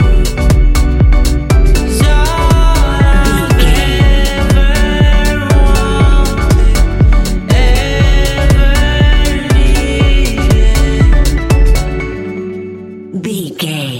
Ionian/Major
E♭
house
electro dance
synths
techno
trance
instrumentals